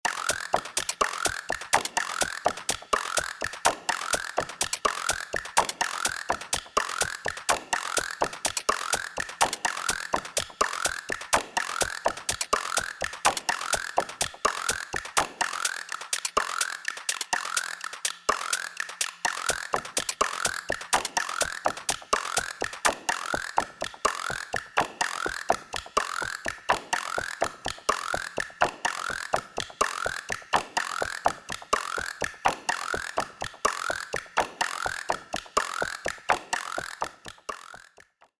This is mostly a rehash of my Guiro sound that I made at one point for some ‘latin percussion’ sounds. The Clap makes a pretty good hat as well though :slight_smile:
That’s a damn good guiro :sunglasses: